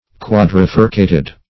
Search Result for " quadrifurcated" : The Collaborative International Dictionary of English v.0.48: Quadrifurcated \Quad`ri*fur"ca*ted\, a. [Quadri- + furcated.] Having four forks, or branches.